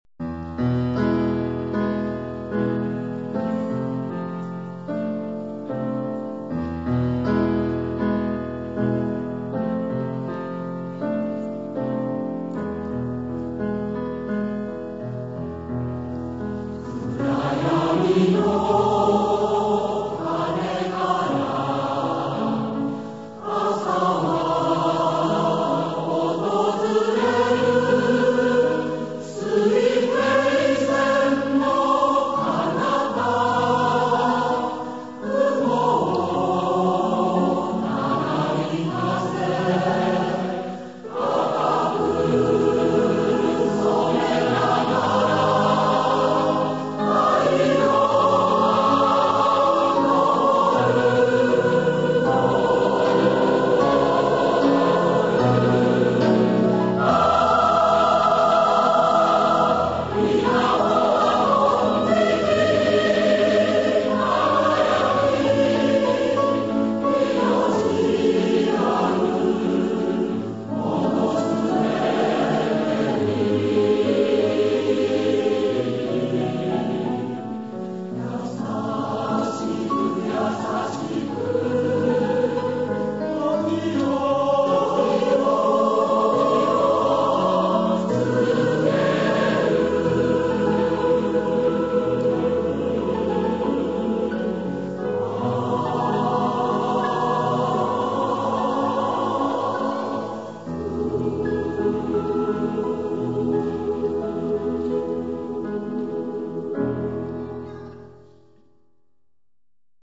演奏：九州のうたごえ合同合唱団（２００３年九州のうたごえ大分祭典での録音）